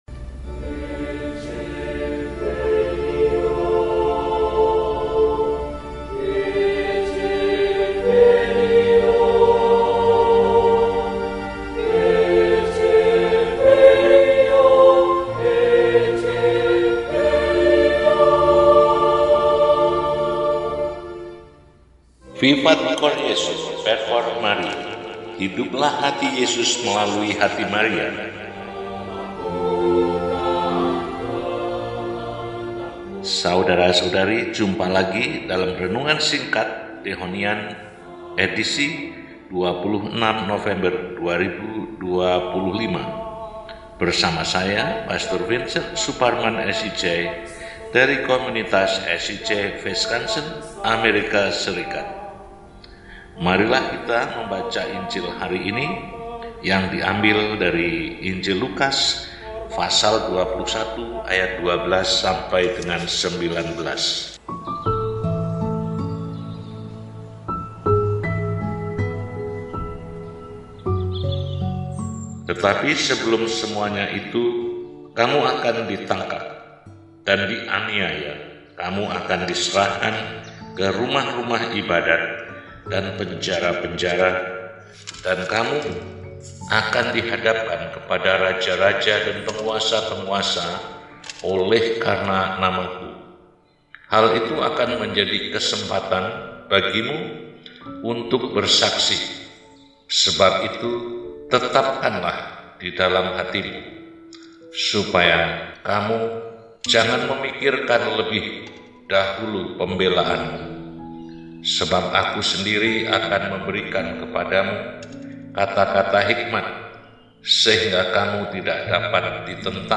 Rabu, 26 November 2025 – Hari Biasa Pekan XXXIV – RESI (Renungan Singkat) DEHONIAN